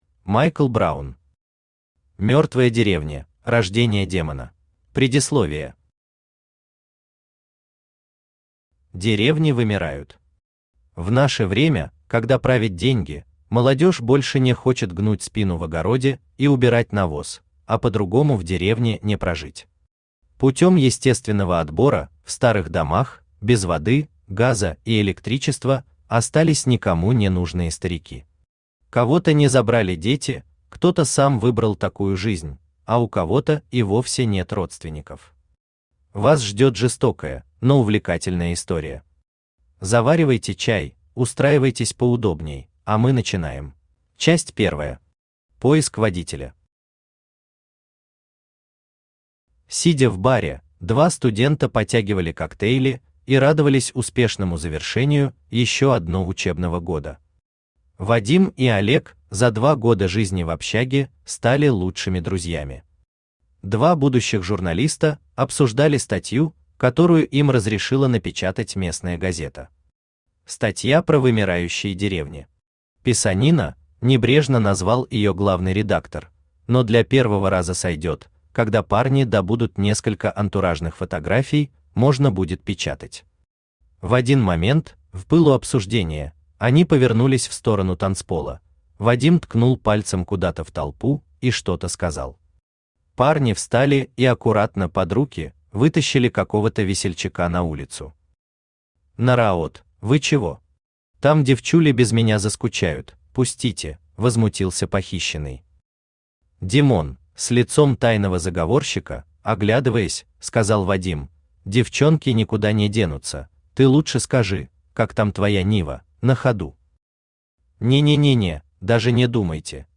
Аудиокнига Мертвая деревня: рождение демона | Библиотека аудиокниг
Aудиокнига Мертвая деревня: рождение демона Автор Майкл Бобби Браун Читает аудиокнигу Авточтец ЛитРес.